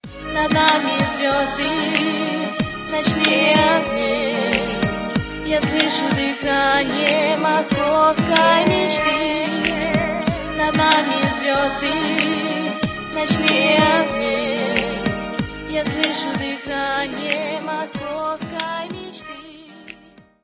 рэп музыка